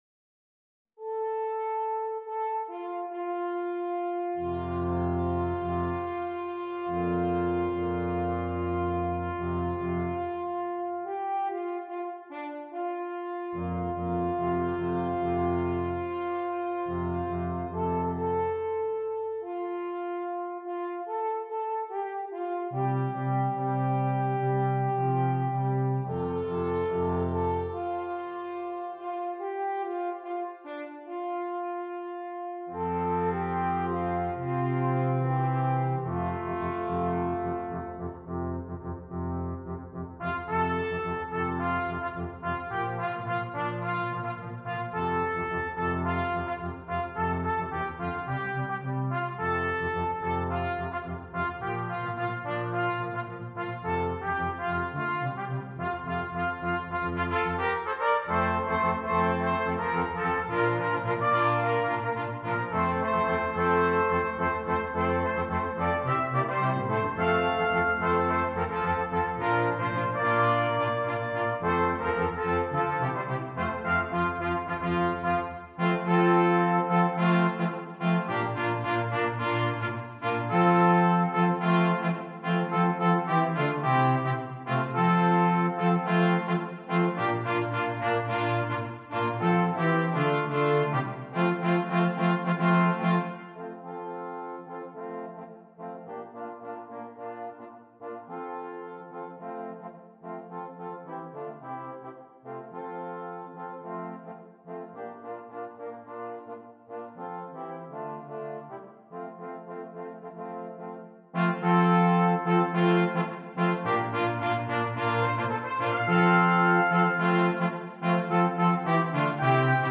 Brass Quintet
Traditional South African Song